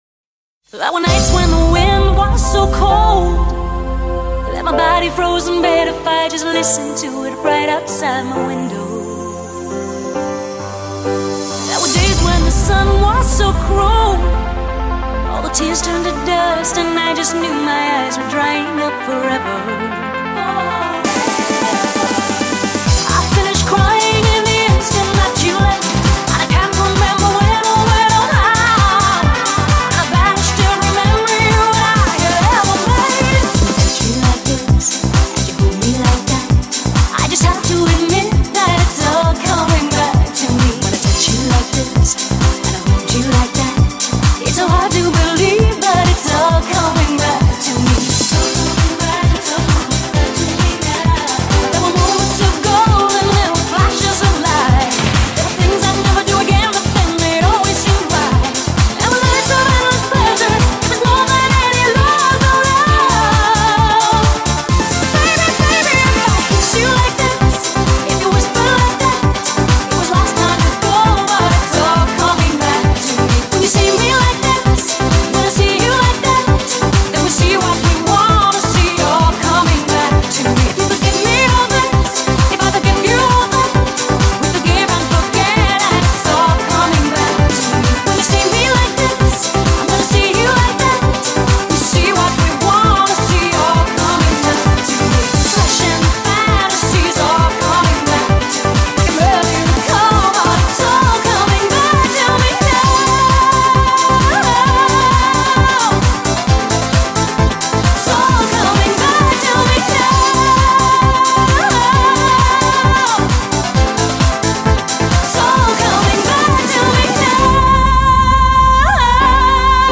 BPM66-132
Audio QualityPerfect (High Quality)
An Italo-dance cover